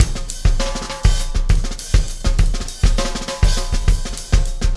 Extra Terrestrial Beat 06.wav